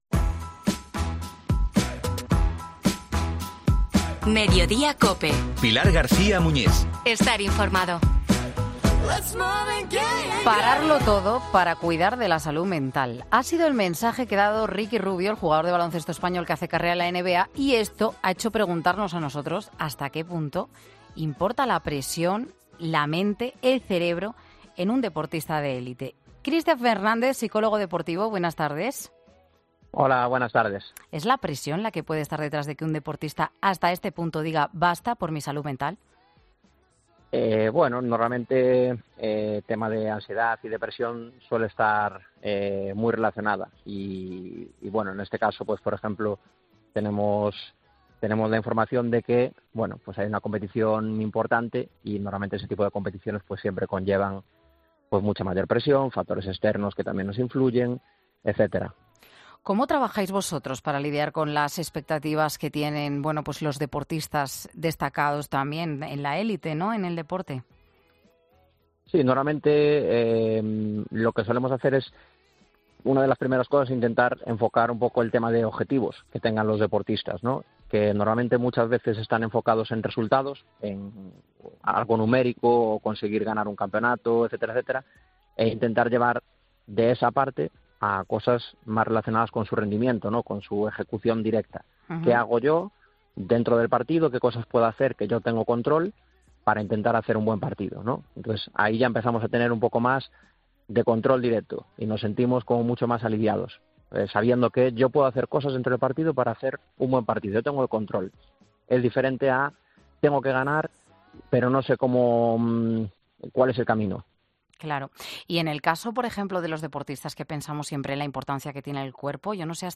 La presión de la élite: Un psicólogo explica cómo tratar de evitar casos como el de Ricky Rubio